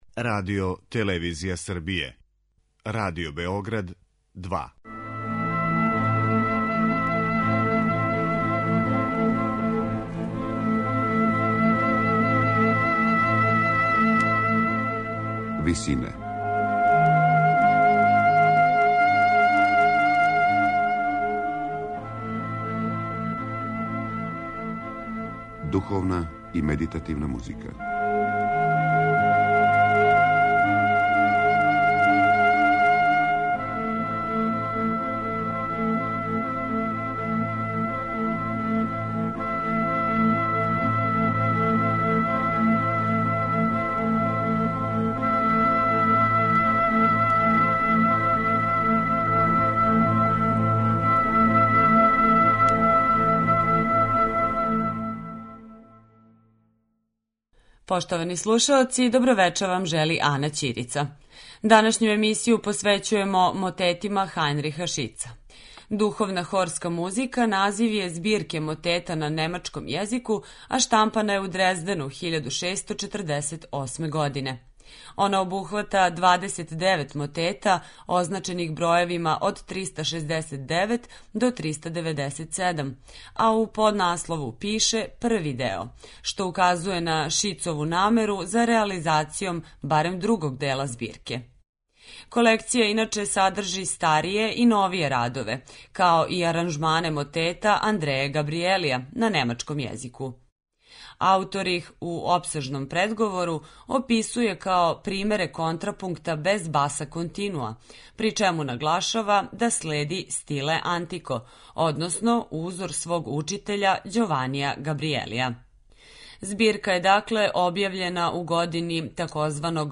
Слушаћете одабране мотете из збирке 'Духовна хорска музика' Хајнриха Шица.